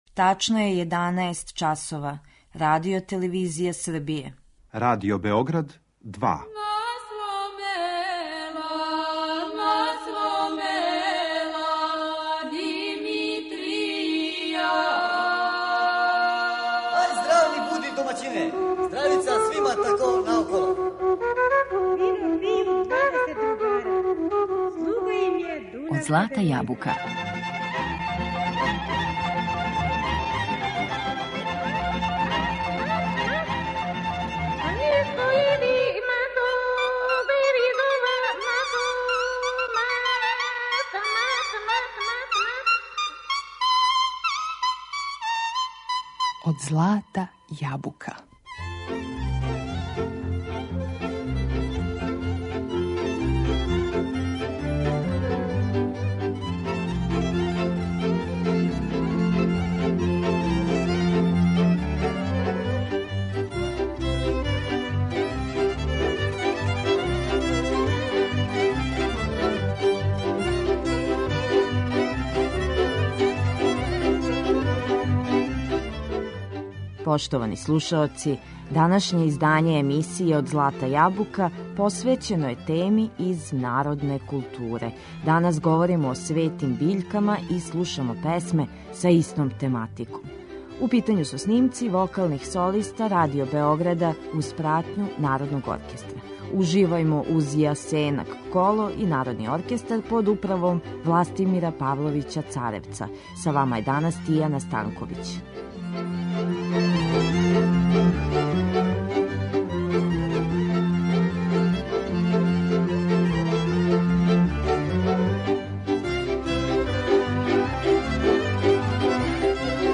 Говорићемо о светим биљкама и слушати песме посвећене тој тематици. У питању су снимци вокалних солиста Радио Београда уз пратњу Народног оркестра.